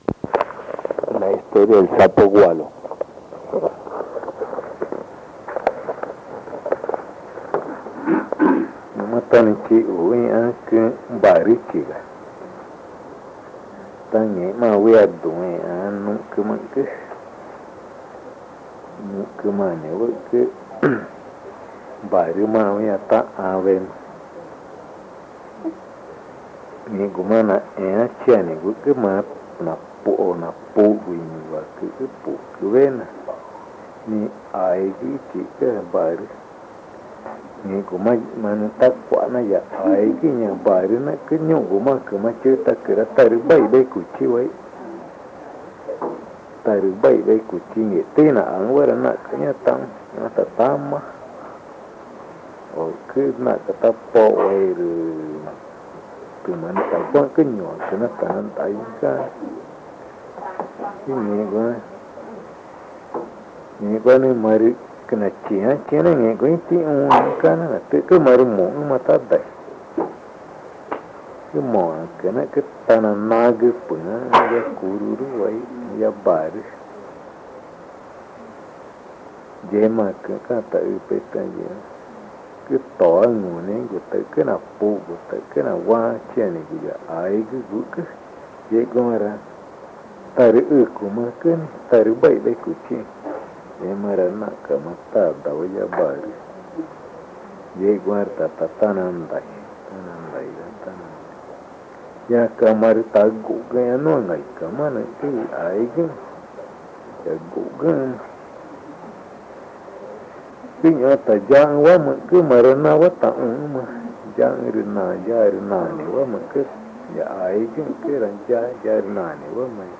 Río Amazonas (Colombia), problamente Pozo Redondo